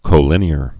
(kō-lĭnē-ər)